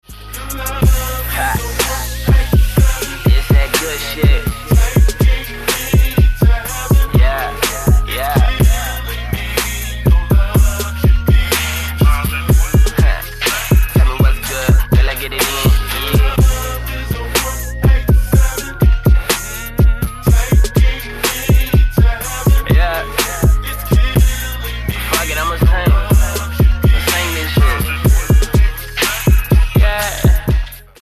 Хип-хоп
спокойные
Rap
Стиль: Hip-Hop